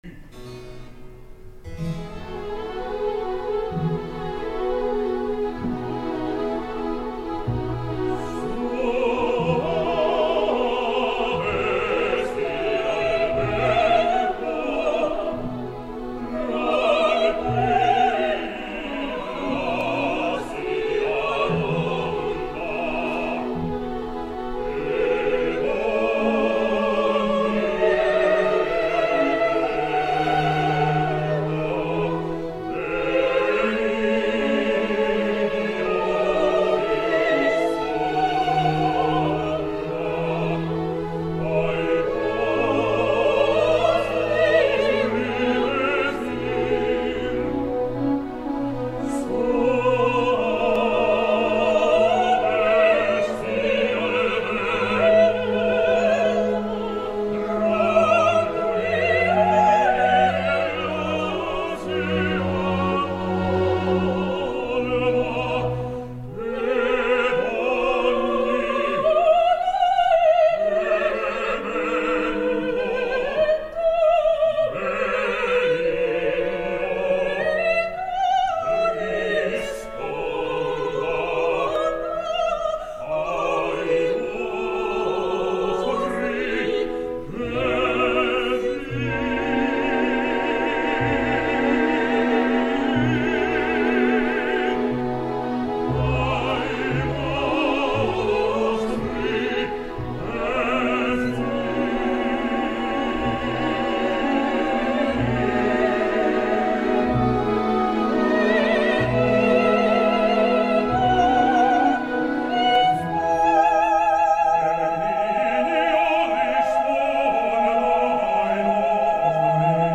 Comencem amb el famós i cinematogràfic tercet “Soave sia il vento”, malaurat per la manca d’extasiada i melangiosa quietud que Mozart proposa i que Conlon menysprea miserablement.
LA Opera, 18 de setembre de 2011.